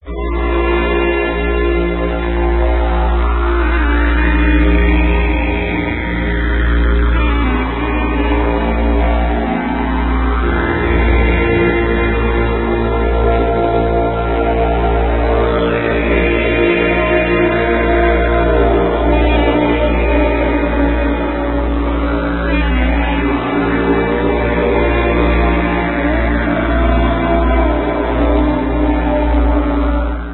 New Age